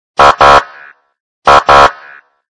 » гудок Размер: 28 кб